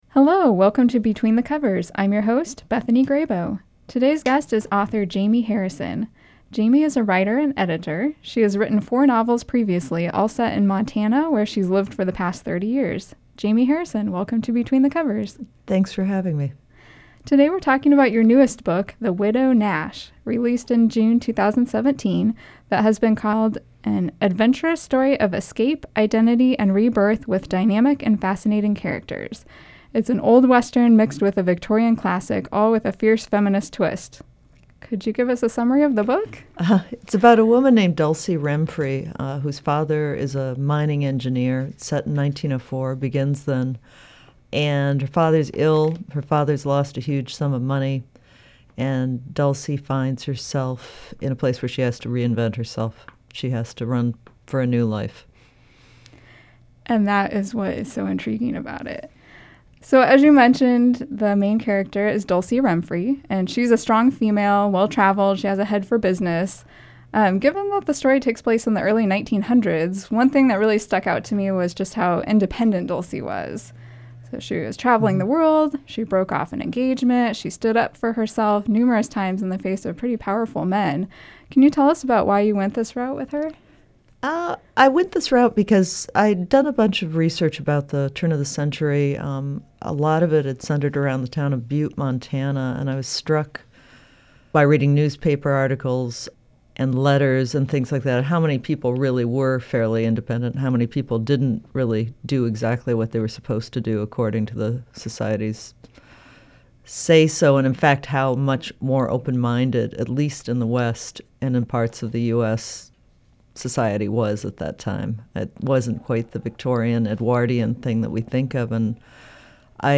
Topic tags: Fiction